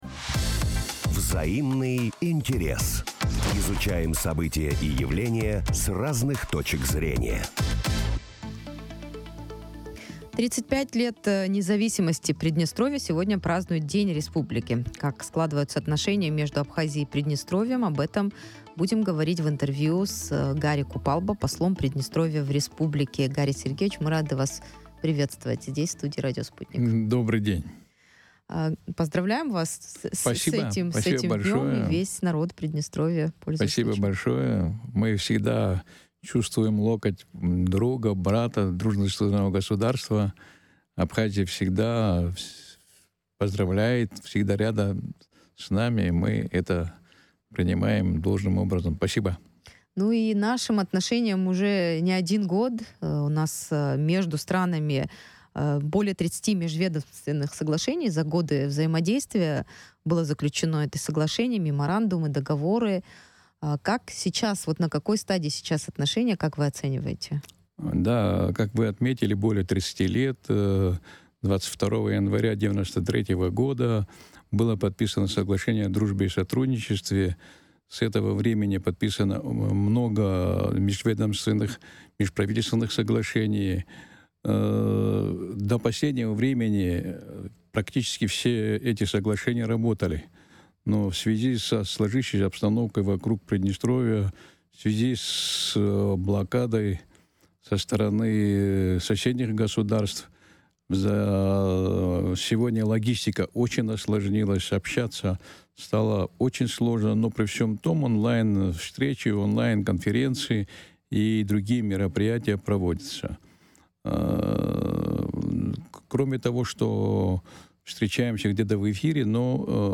35 лет со дня образования ПМР. Интервью с послом Приднестровья в Абхазии
Приднестровье сегодня отмечает 35-летие независимости республики. Как складываются отношения между ПМР и Абхазией, в эфире радио Sputnik говорили с послом Приднестровья.